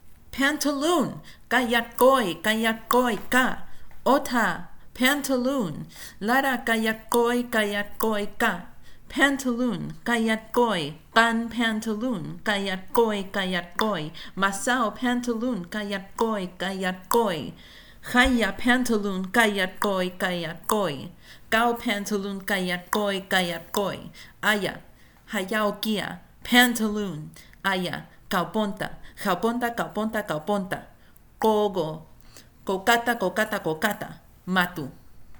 This lack of clarity is not related to the fact it has a thousand phonemes, but to the hard-to-follow interplay between clicks, nasalization, and the aforementioned nasalized vowels which muddies speech, making things harder to understand.
Sample Sentence